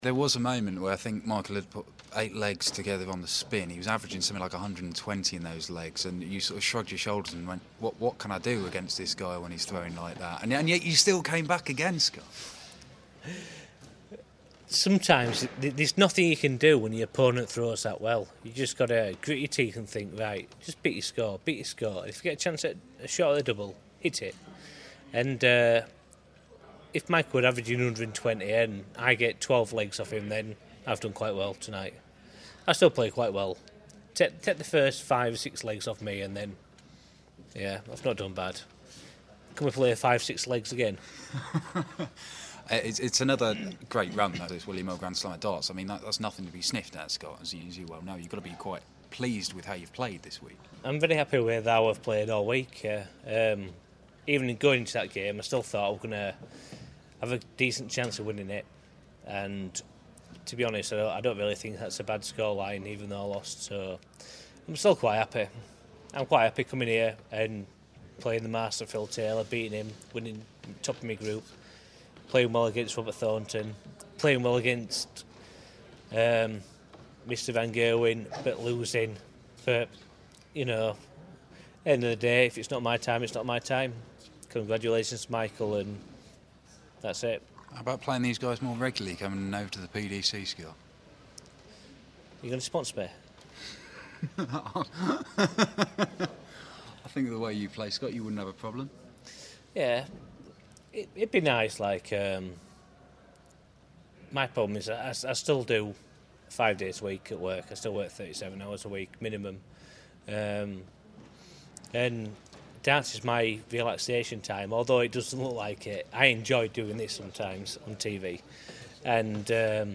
William Hill GSOD - Waites Interview Part Two (QF)